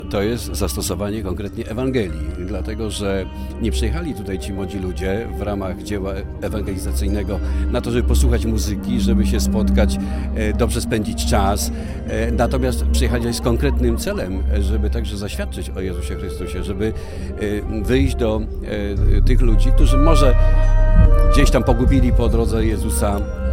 Chrystus żyje, On jest naszą nadzieją, jest najpiękniejszą młodością tego świata – te słowa Papieża Franciszka z adhortacji Christus vivit niech szczególnie nam towarzyszą – powiedział na otwarcie inicjatywy ewangelizacyjnej Przyjaciele Jezusa Biskup Tadeusz Lityński do zgromadzonych.